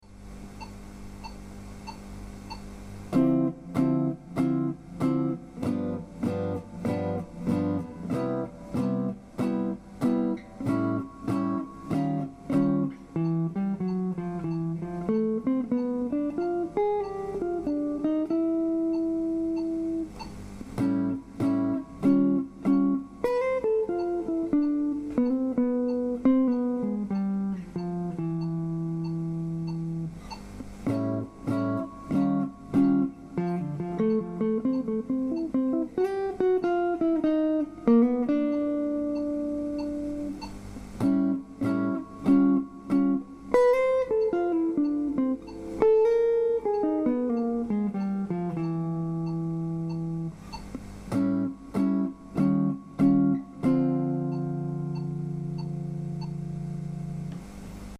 Lesson.18 　・メジャーⅡ－Ⅴ－Ⅰのフレーズ
まずは基本的なフレーズを集めてきたので練習しましょう。